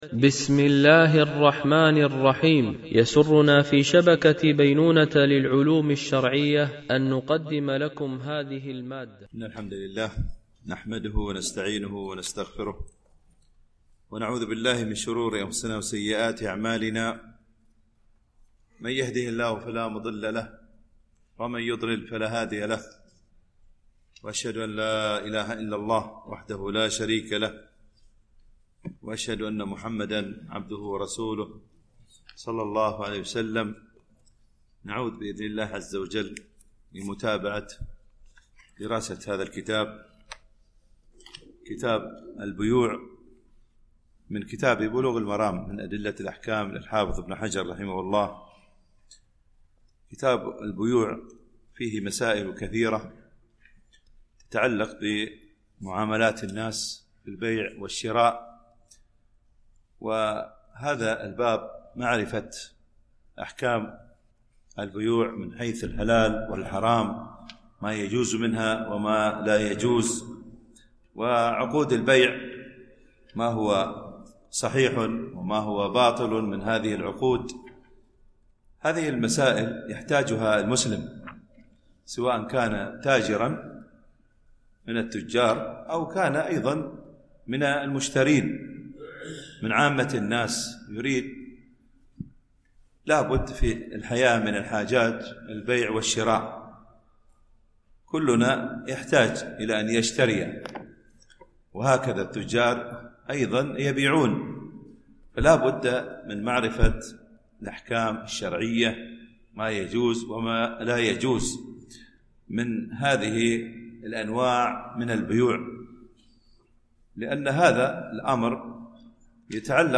شرح بلوغ المرام من أدلة الأحكام - الدرس 180 ( كتاب البيوع - الجزء ١٤- الحديث 822 - 826 )